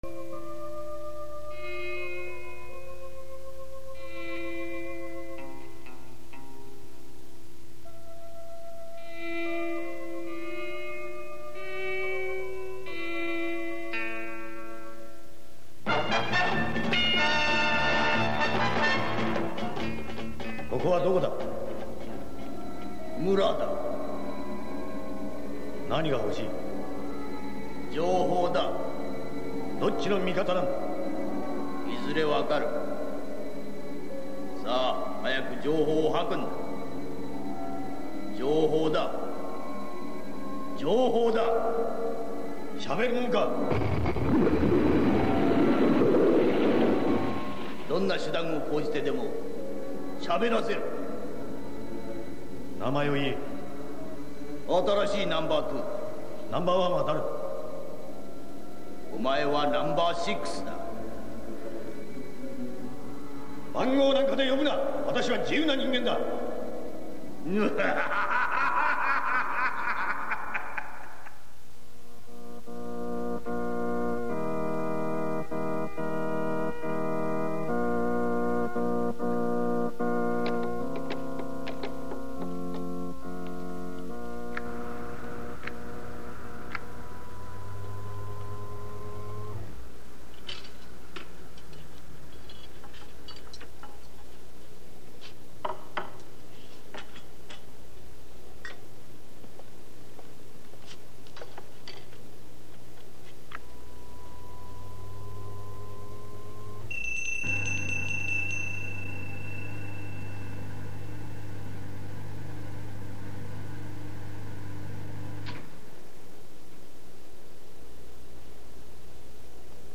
このテープ音声は、そもそもはモノーラルな家庭用オープンリールデッキで録音した物で、現時点ではカセットにダビングしたものしか残っていません。
Once upon a Time（最後の対決）の冒頭、No.2の語り(mp3音声ファイル)